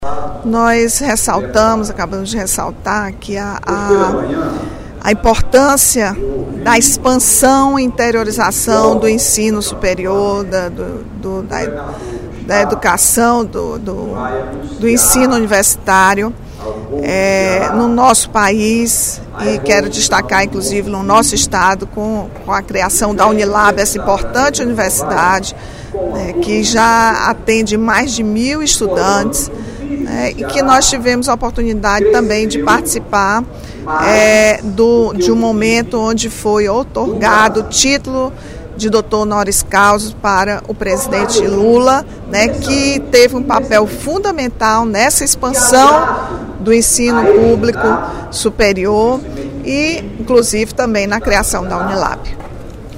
No primeiro expediente da sessão plenária desta terça-feira (05/03), a deputada Rachel Marques (PT) parabenizou o ex-presidente Lula, que recebeu, na última sexta-feira (01/03), o título de Doutor Honoris Causa da Universidade da Integração Internacional da Lusofonia Afro-Brasileira (Unilab).
Em aparte, o deputado Leonardo Pinheiro (PSD) destacou a importância da universalização do ensino superior.